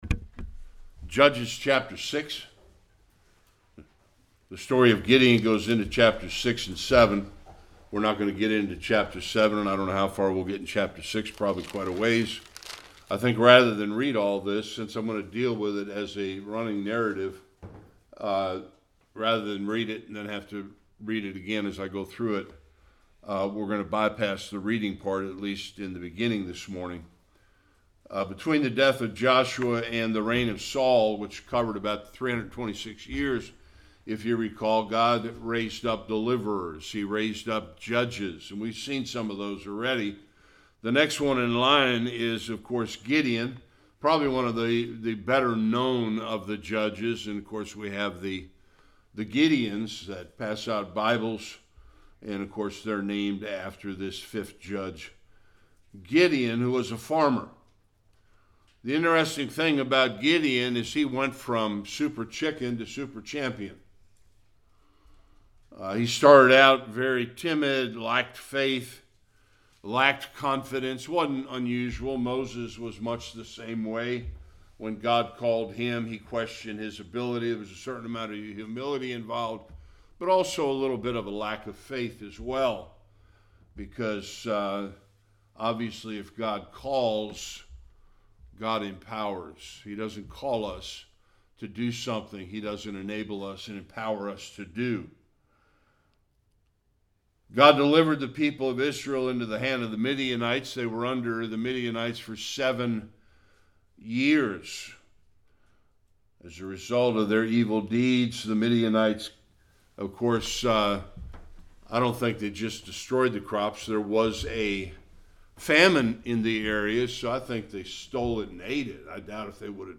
1-40 Service Type: Sunday School Gideon’s call.